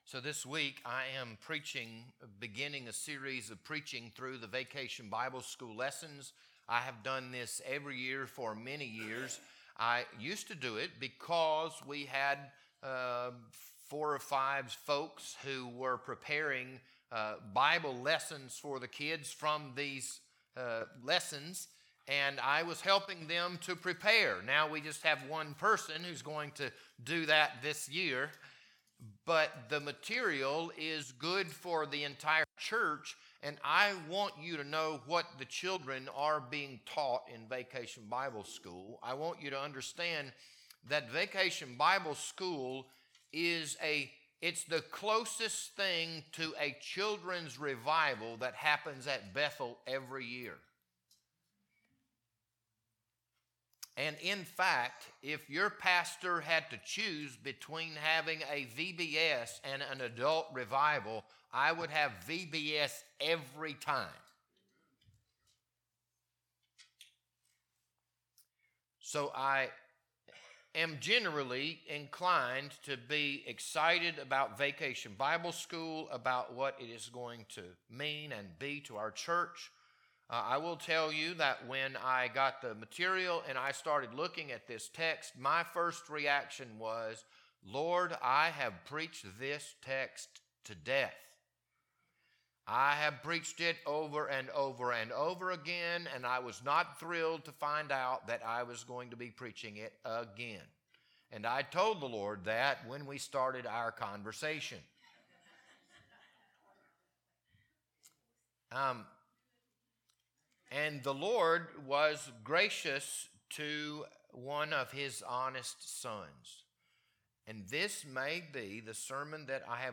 This Sunday morning sermon was recorded on April 19th, 2026.